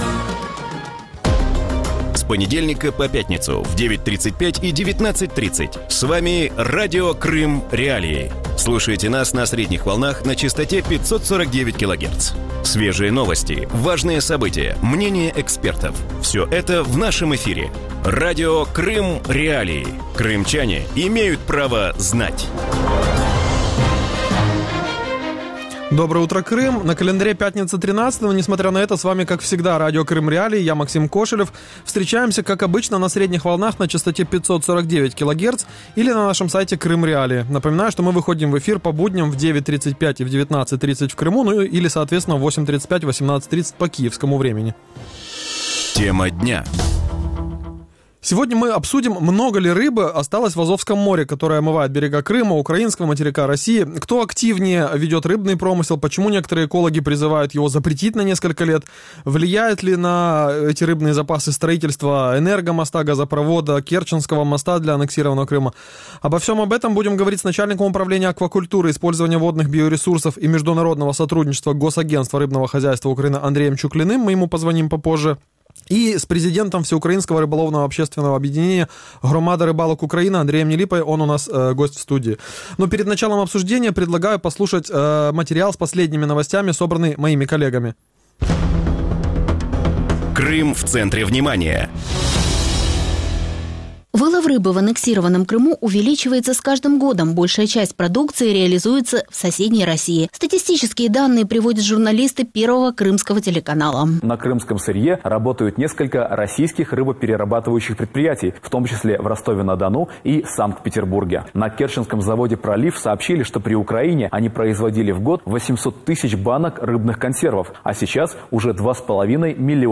В утреннем эфире Радио Крым.Реалии говорят о добыче рыбы в Азовском море. Какая ситуация с популяцией рыбы в акватории? Как влияет на популяцию строительство энергомоста, газопровода и Керченского моста для аннексированного полуострова?